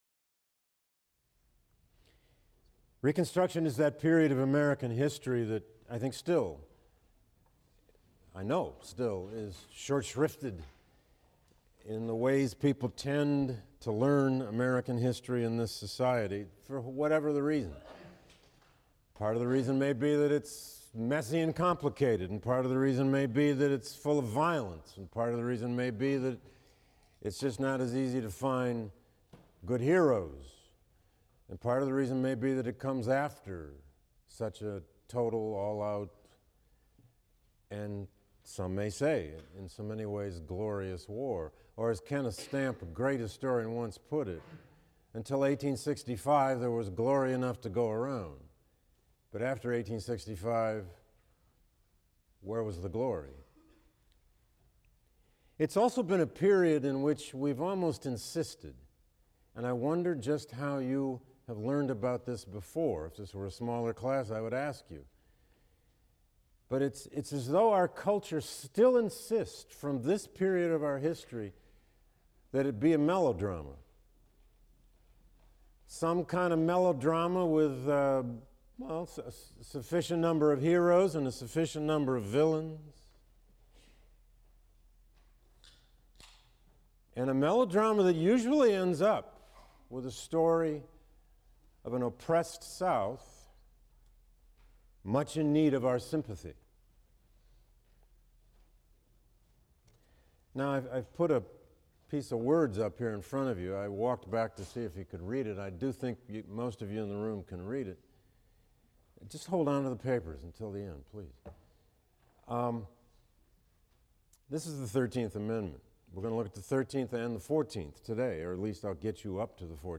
HIST 119 - Lecture 21 - Andrew Johnson and the Radicals: A Contest over the Meaning of Reconstruction | Open Yale Courses